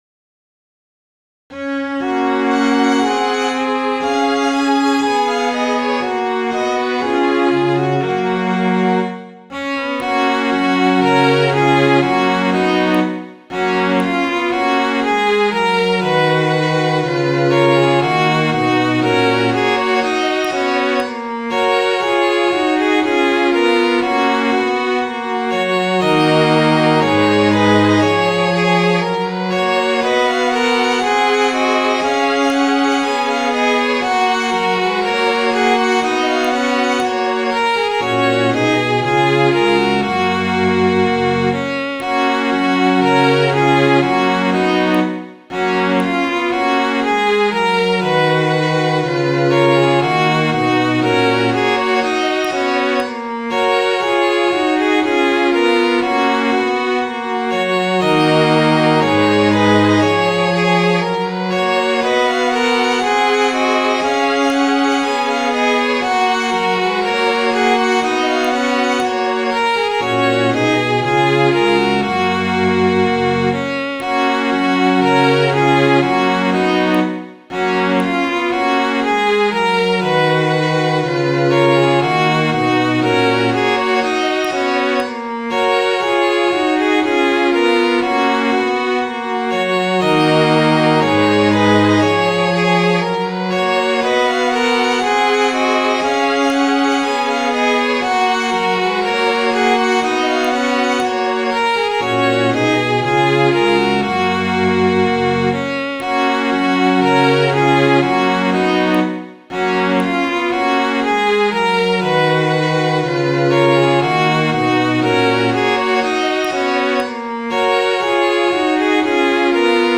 Midi File, Lyrics and Information to The Wealthy Farmer's Son